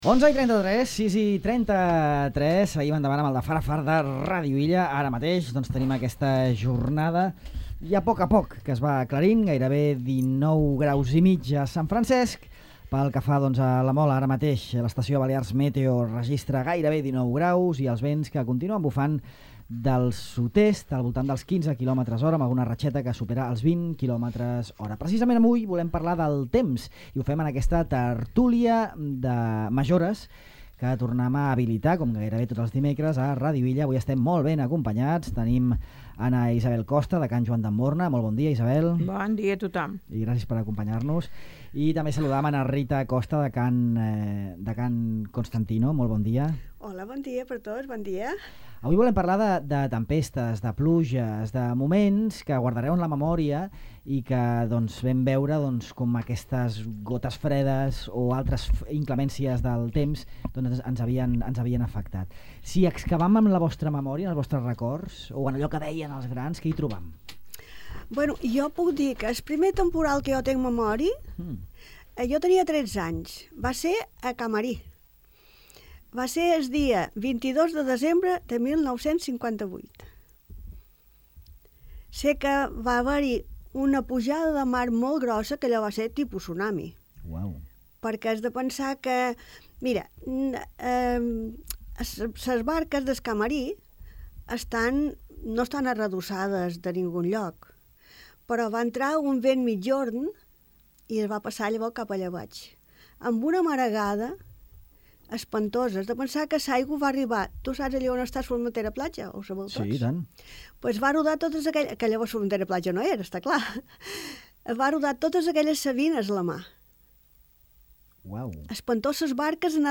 Tertúlia de majores: les tempestes que han quedat en la memòria, 1958, 1985…